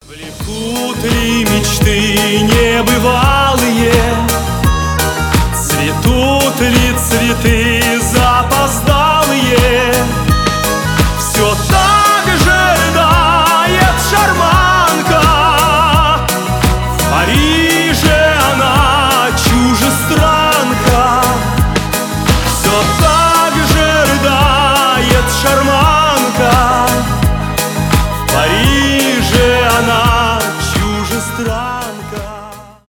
эстрадные
поп